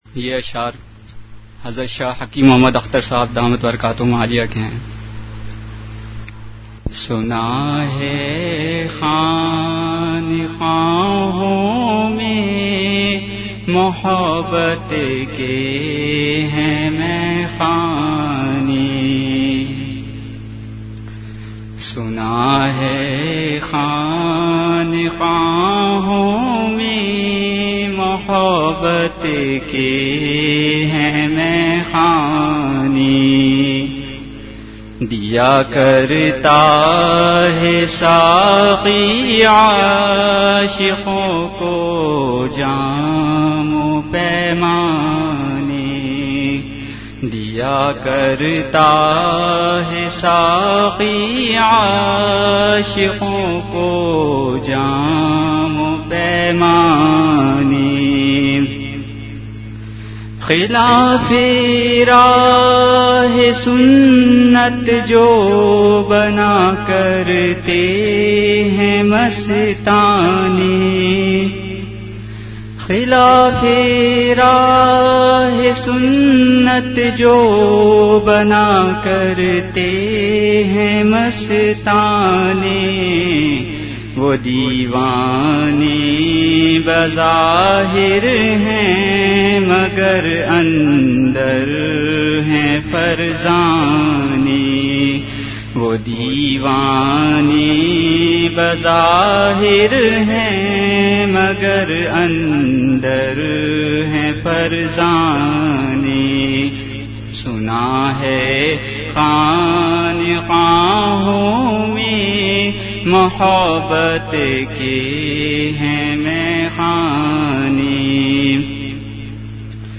Category Majlis-e-Zikr
Event / Time After Isha Prayer